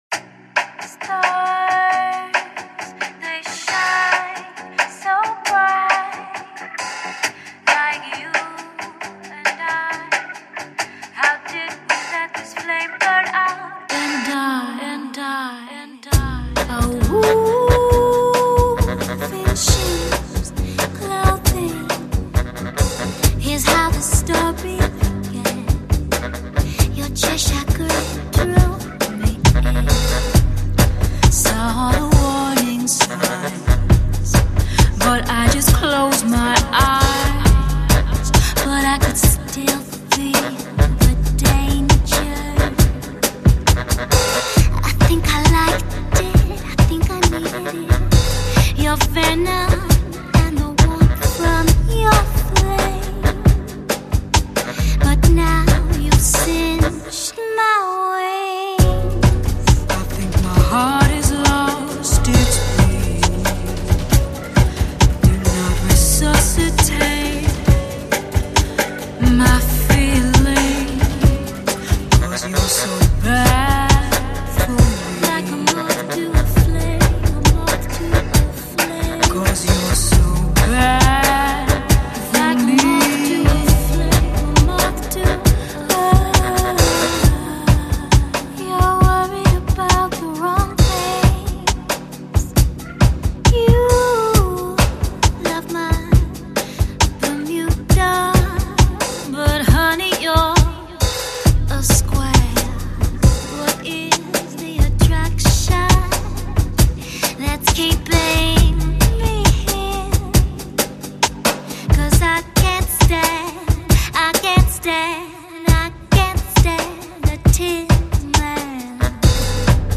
# Pop Fuzion